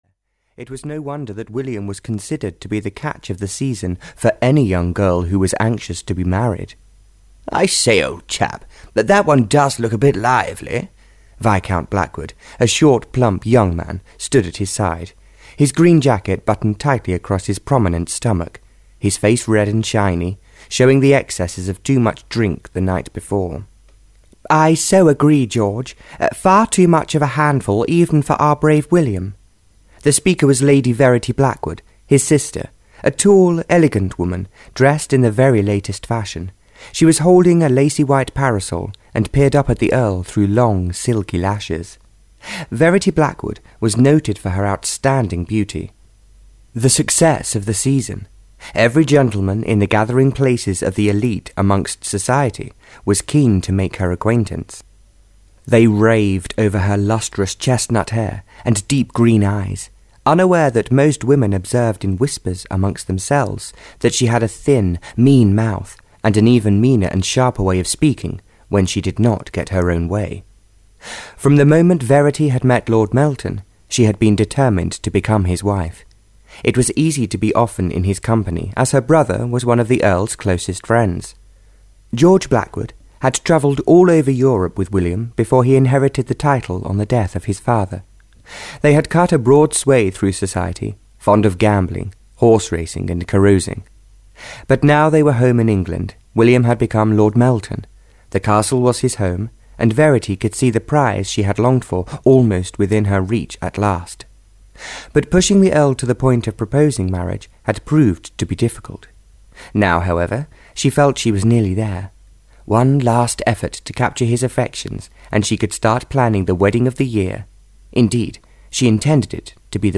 Audio knihaLove Rescues Rosanna (Barbara Cartland’s Pink Collection 19) (EN)
Ukázka z knihy